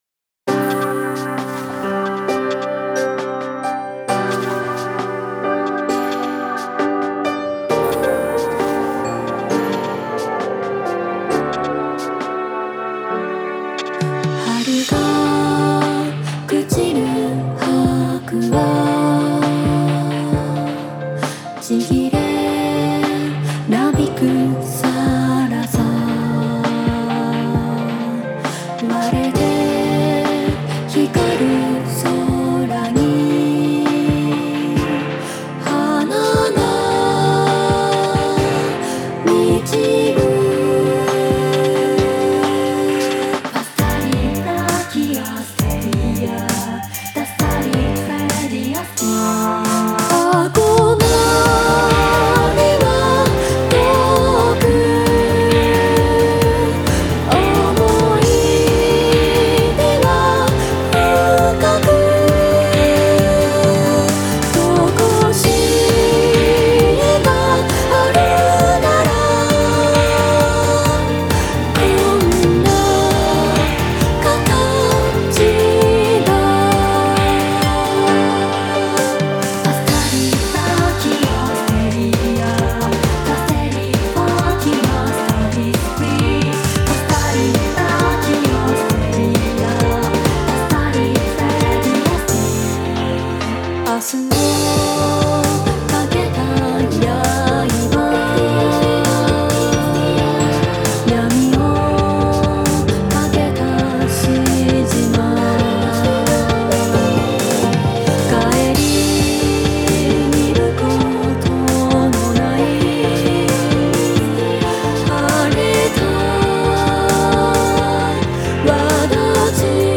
切なめでファンタジックなボーカル曲です。多重録音のコーラスを入れました。ループしません。